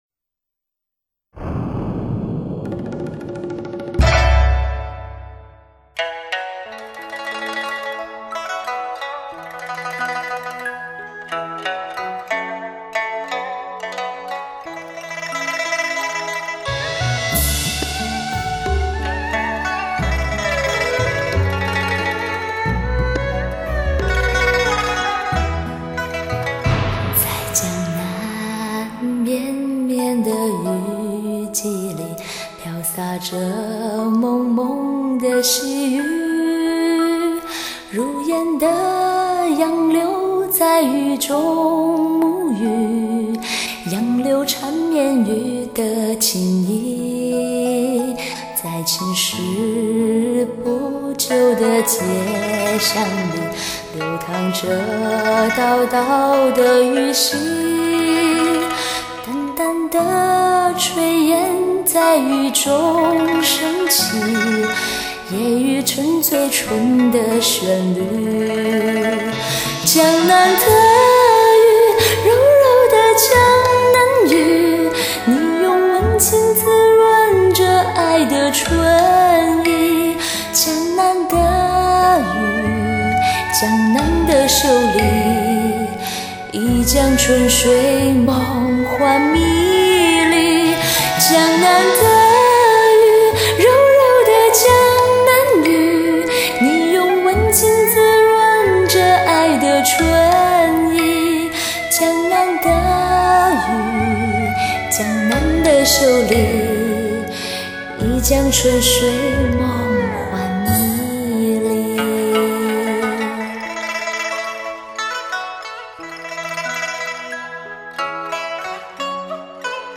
近乎吻语般的女声
不是唱，而是吟 近乎吻语般的女声，